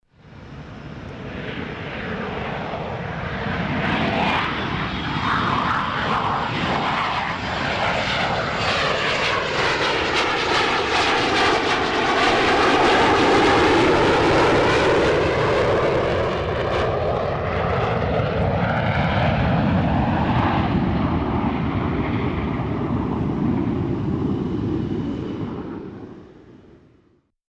F-18 hornet jet fighter, take off